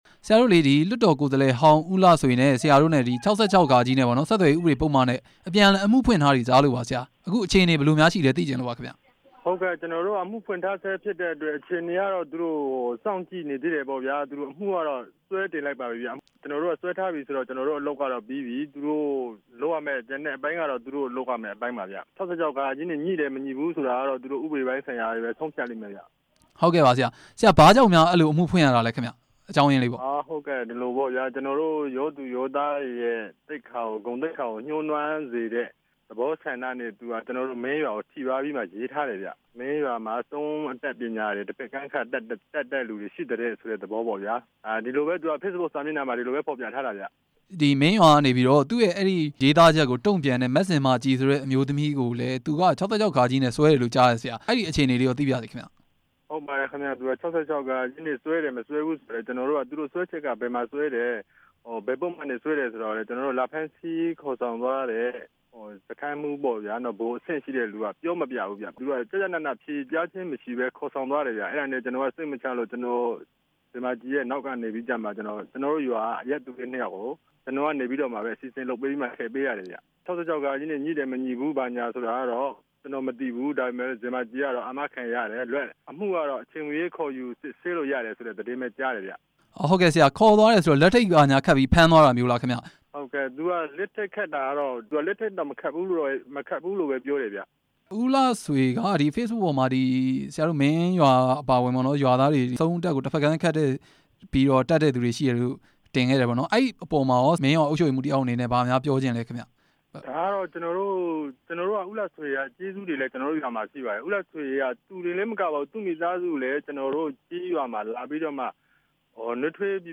ဦးလှဆွေ ကို အမှုဖွင့်တိုင်ကြားထားသူနဲ့ မေးမြန်းချက်